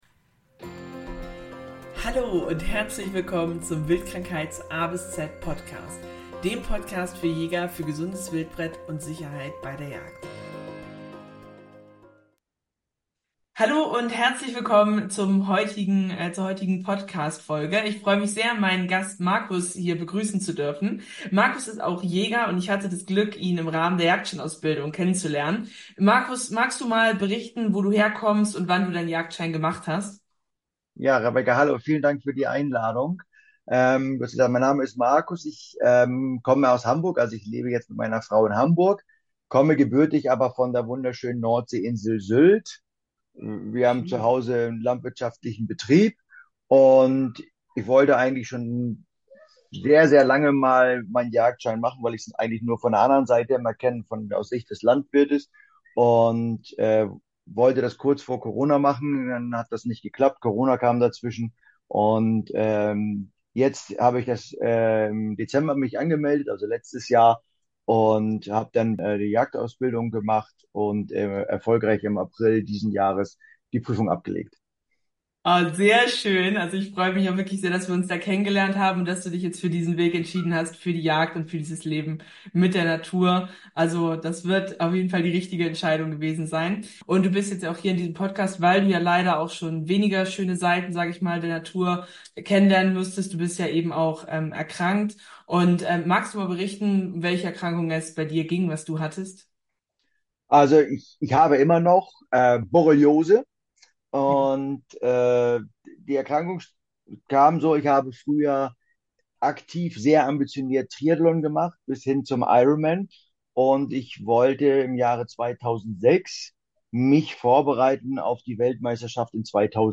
Beschreibung vor 1 Jahr Folge 2: Zeckenbiss mit Folgen – ein Borreliose-Erfahrungsbericht In dieser Folge spreche ich mit einem Jäger, der durch einen Zeckenbiss an Borreliose erkrankte – eine Krankheit, die sein Leben stark beeinflusst hat. Er berichtet, wie er die ersten Symptome bemerkte, welche Herausforderungen er während der Behandlung erlebte und welche Lektionen er daraus gezogen hat.